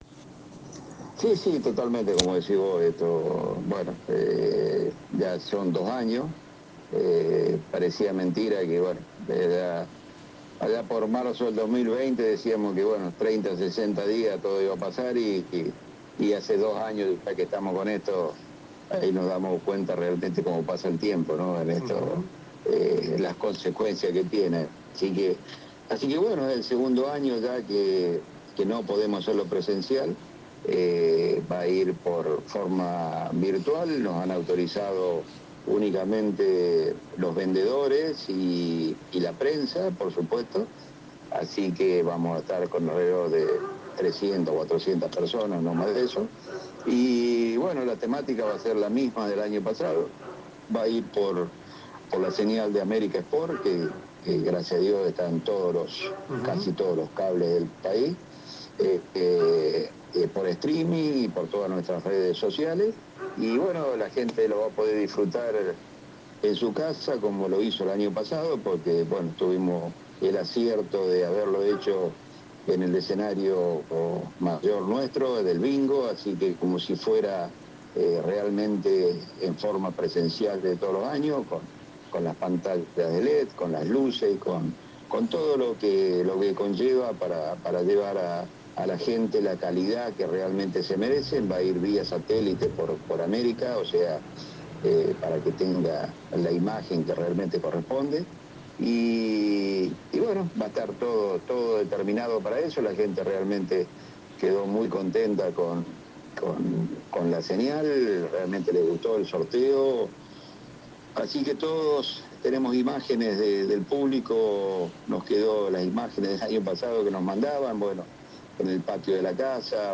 brindó detalles en nuestra emisora sobre la decisión de que el bingo no sea presencial el próximo 5 de Febrero .